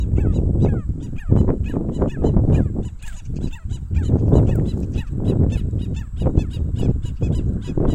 Pantanal Snipe (Gallinago paraguaiae)
especie adicional Tero-real
Detailed location: Dique La Angostura
Condition: Wild
Certainty: Recorded vocal